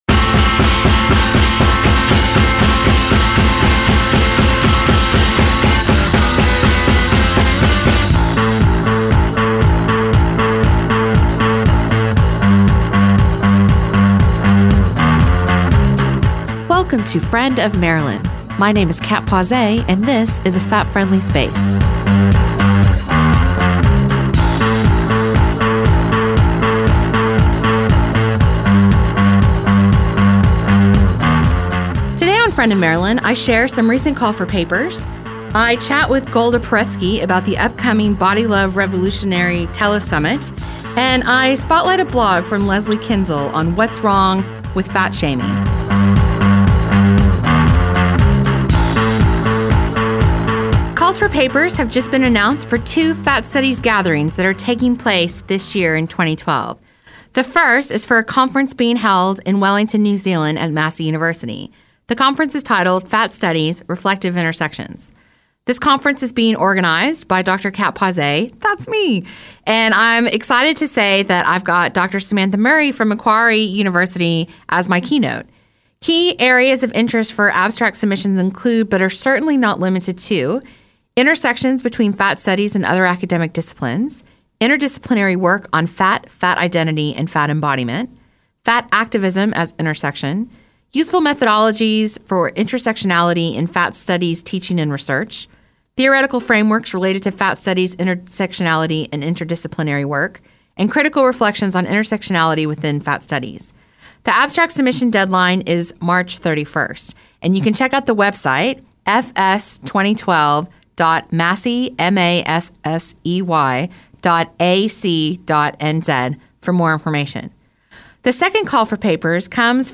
Friend of Marilyn was listened to around the world on multiple platforms and was broadcast locally by Manawatū People’s Radio online and on 999AM.
This episode has been edited from version originally broadcast to remove music by Gossip as rights and licences were not obtained by Manawatū Heritage.